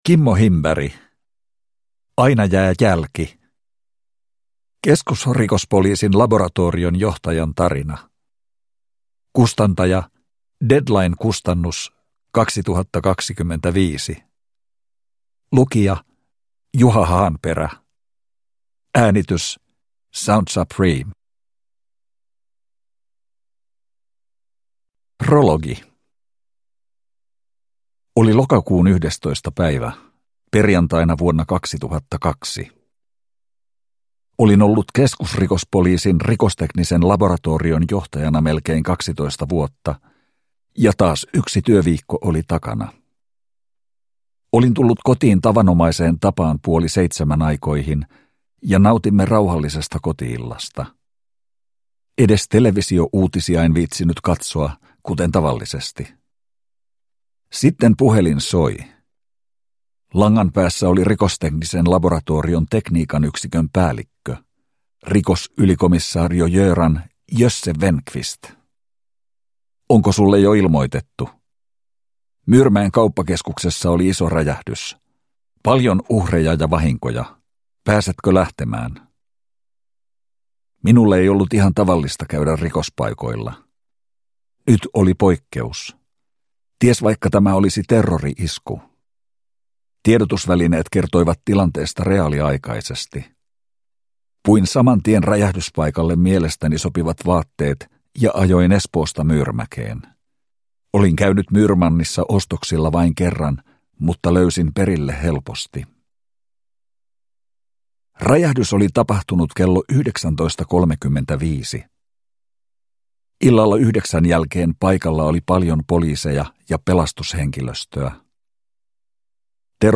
Aina jää jälki – Ljudbok